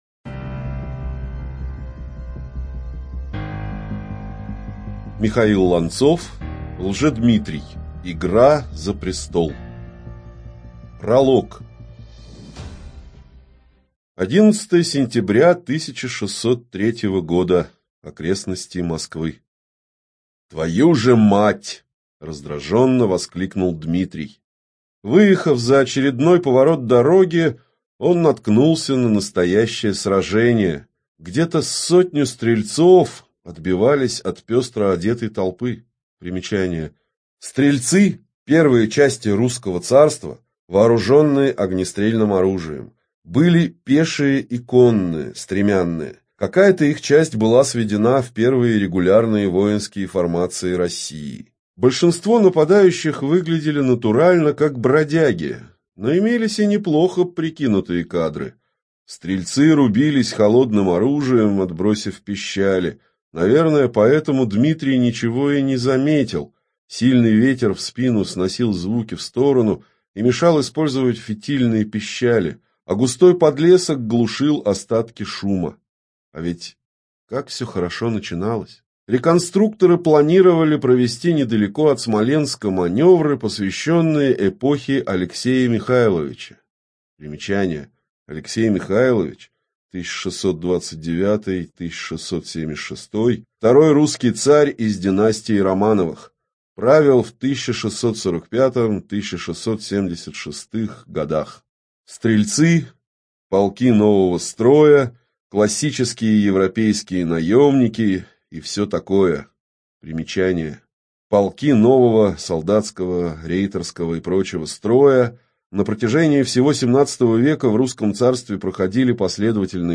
ЖанрФантастика, Альтернативная история